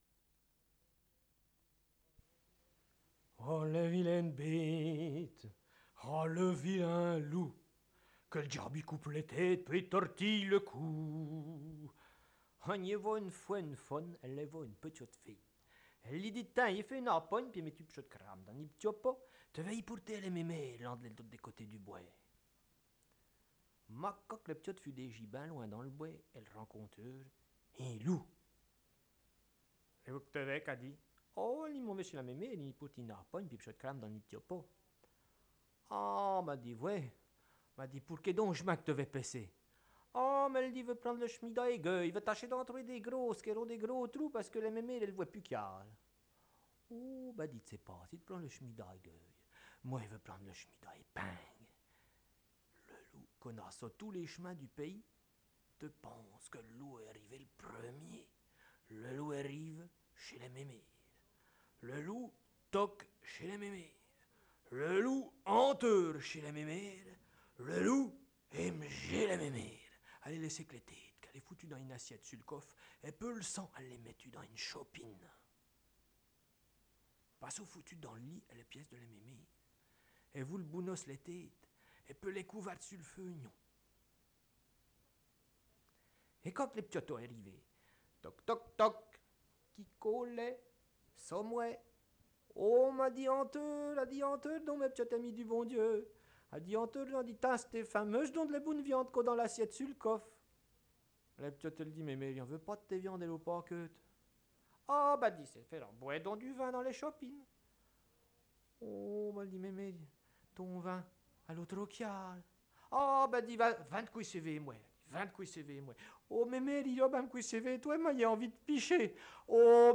contée